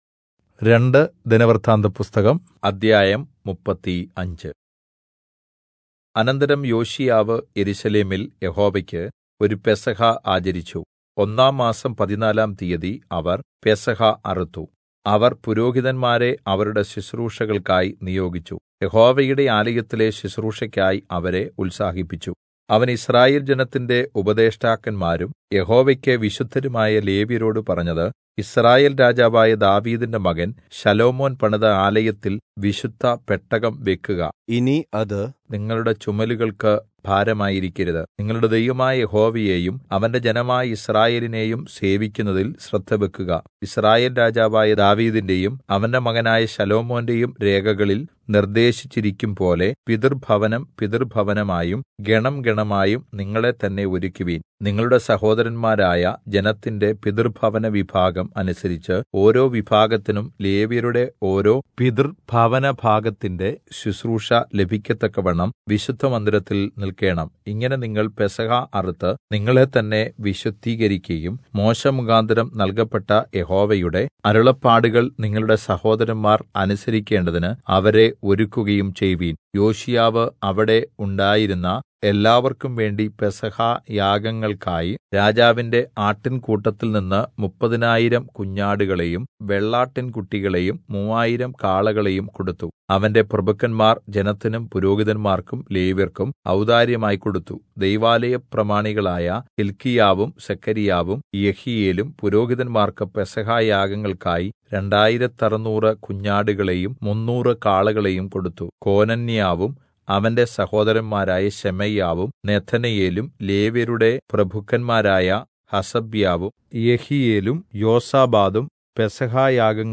Malayalam Audio Bible - 2-Chronicles 7 in Irvml bible version